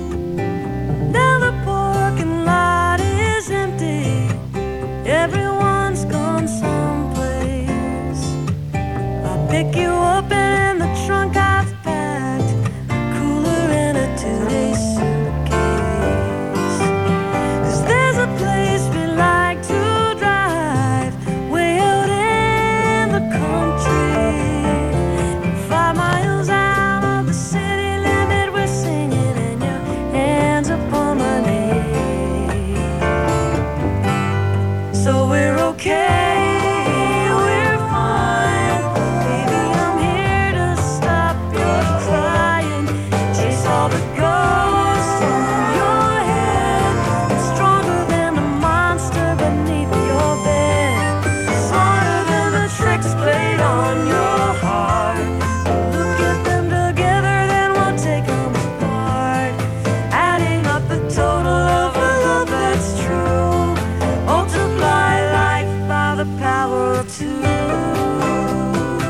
studio